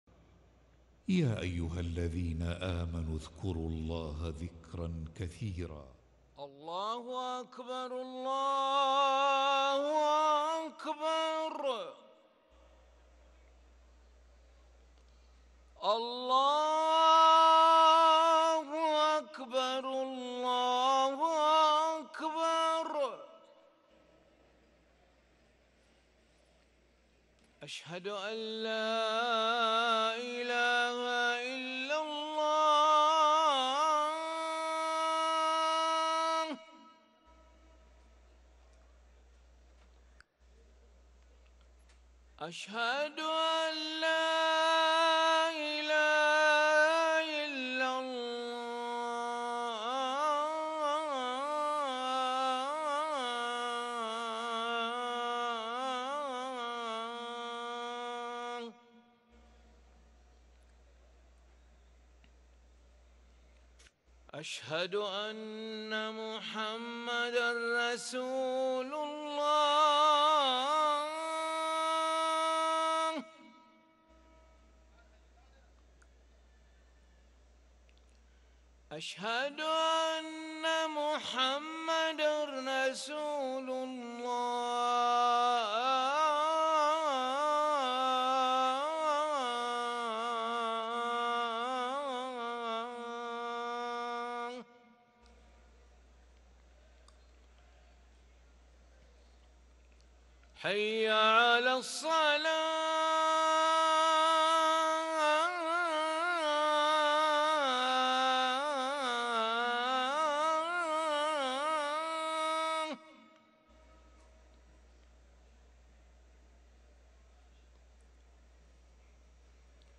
أذان العشاء للمؤذن علي ملا الخميس 2 ربيع الآخر 1444هـ > ١٤٤٤ 🕋 > ركن الأذان 🕋 > المزيد - تلاوات الحرمين